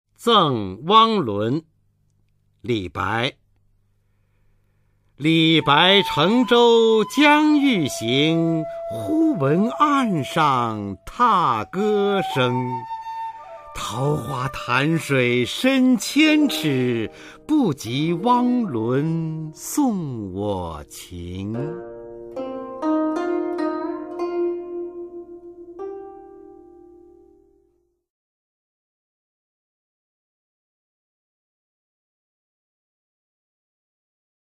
[隋唐诗词诵读]李白-赠汪伦（男） 古诗文诵读